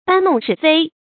bān nòng shì fēi
搬弄是非发音
成语正音 弄，不能读作“lònɡ”。